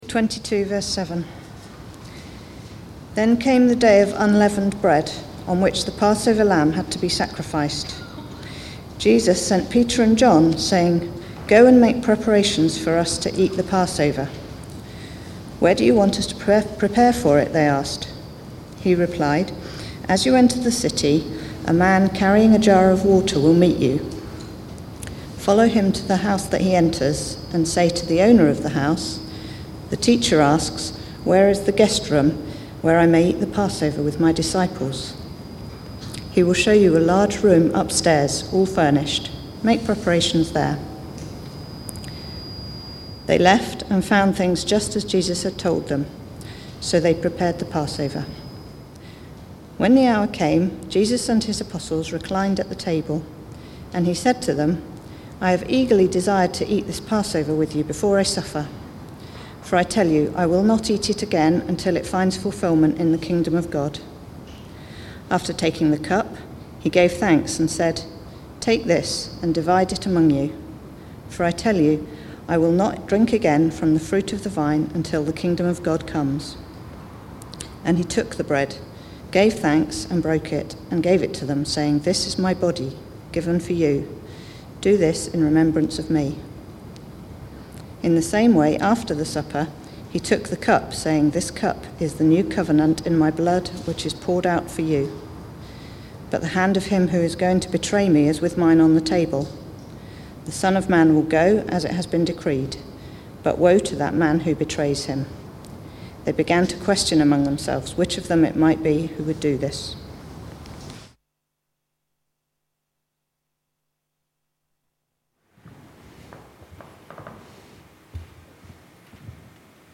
Preaching
The Last Supper (Luke 22:7-23) Recorded at Woodstock Road Baptist Church on 28 March 2021.